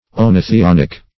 Search Result for " oenothionic" : The Collaborative International Dictionary of English v.0.48: oenothionic \oe`no*thi*on"ic\, a. [Gr. o'i^nos wine + thionic.]